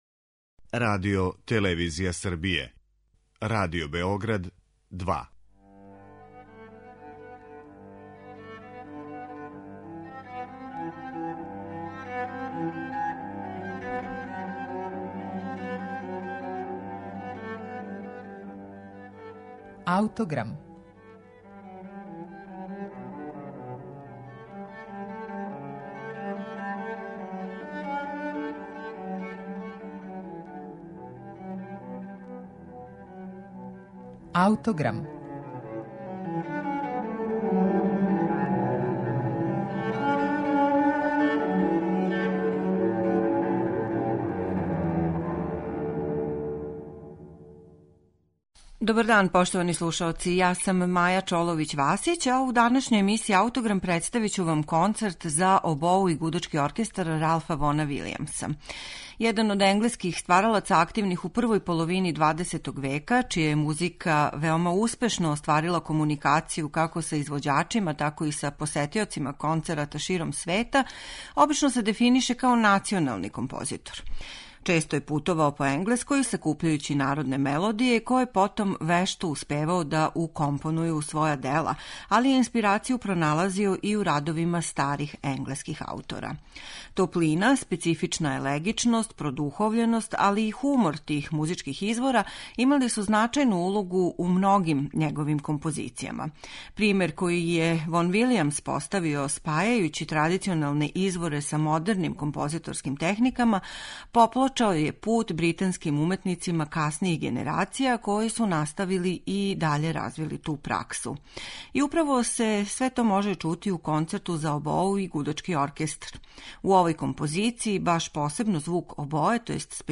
Ралф Вон Вилијамс ‒ Концерт за обоу и гудачки оркестар
По елеганцији и лакоћи овог дела тешко је наслутити да је било завршено током ратне 1943. године.